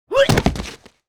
嚎女呻吟倒地-YS070510.wav
通用动作/01人物/03武术动作类/嚎女呻吟倒地-YS070510.wav
• 声道 單聲道 (1ch)